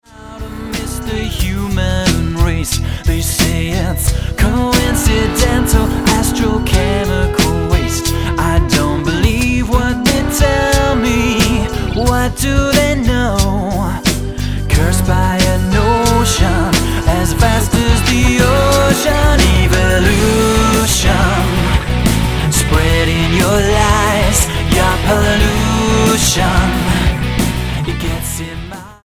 STYLE: Rock
The overall mix is pleasant on the ear and well balanced.